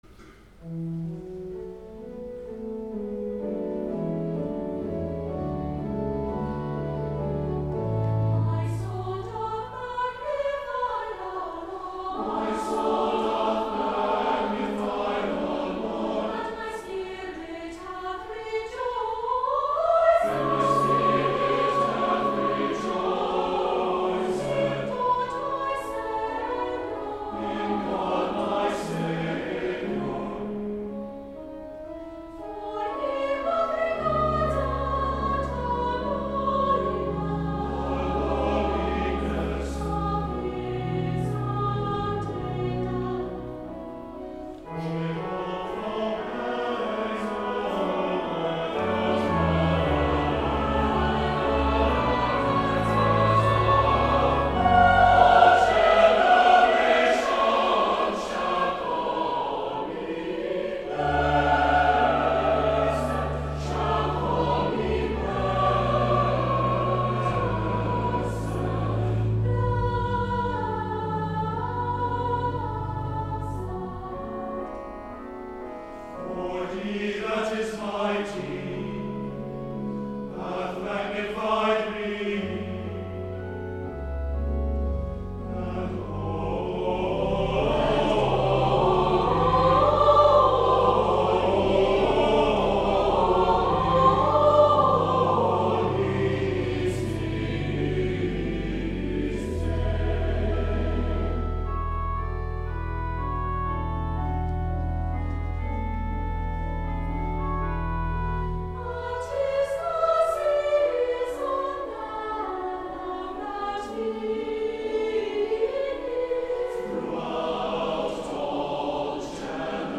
Ecumenical Choral Evensong in observance of The Week of Christian Unity
Cathedral Choir
The Cathedral Brass